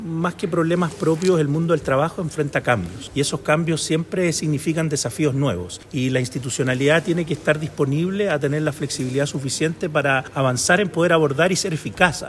Al respecto, el director del Servicio, Pablo Zenteno, afirmó que los desafíos son más bien propios del mundo del trabajo.